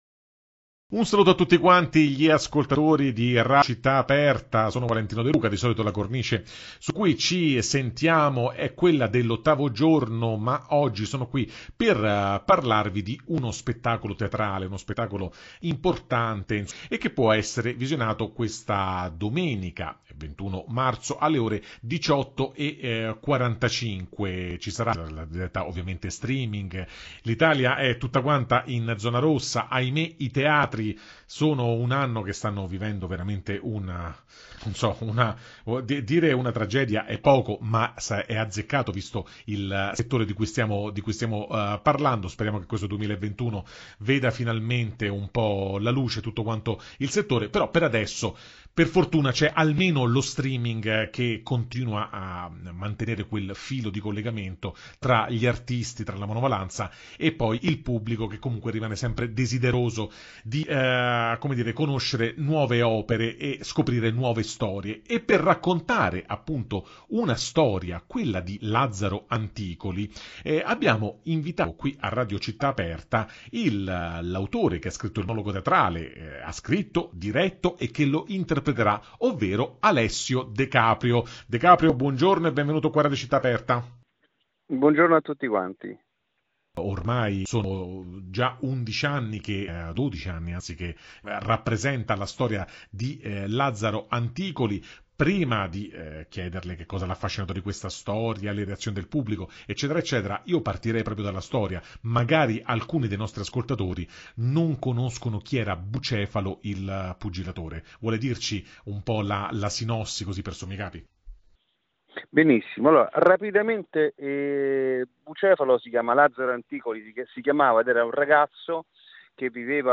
[Intervista]